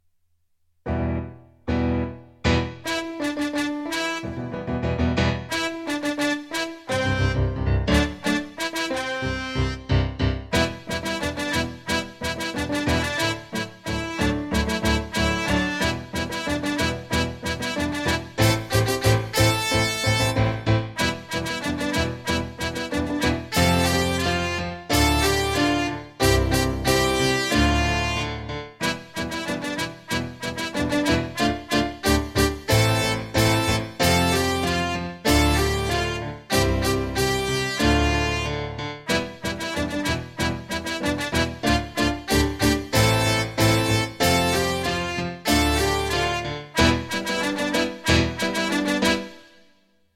Just like in class, every recording will start with three piano chords to get ready before the melody starts and you can sing along (or simply follow along reading the score).  I used a different “instrument” from my keyboard’s sound library for each melody.